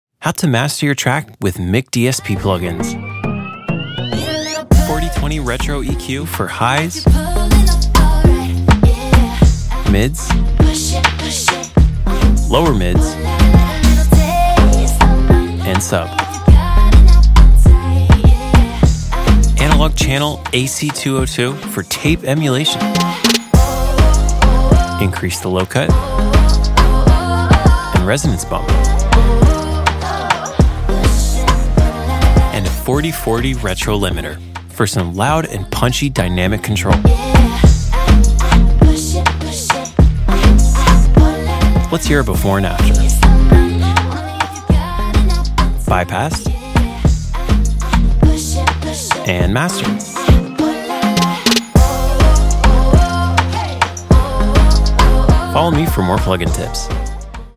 EQ, Tape Saturation, Limiter